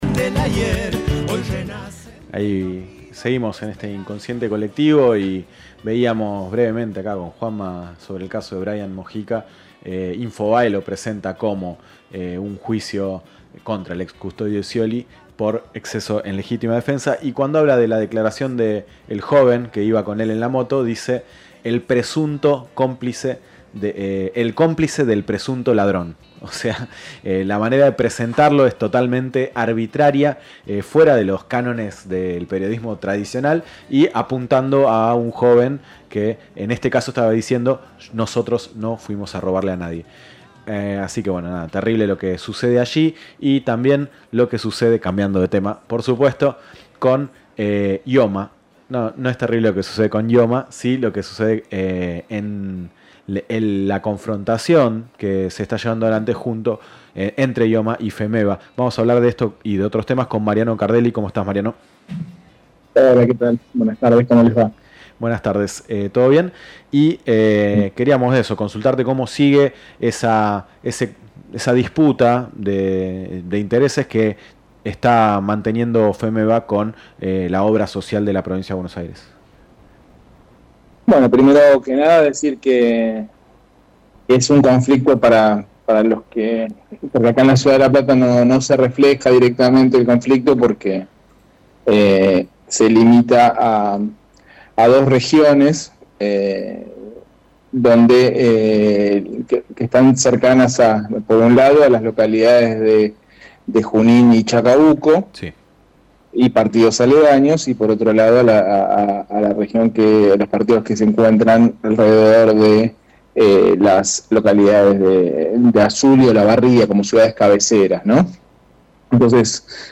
Mariano Cardelli, vicepresidente de IOMA conversó con el programa Inconsciente Kolectivo, que se transmite por Radio Futura de La Plata y FM Punta del Indio, y se retrasmite los domingos de 19 a 22 horas por Radio Estación Sur de La Plata y por FM Difusión Berisso. El tema de la entrevista fue el conflicto con la FEMEBA.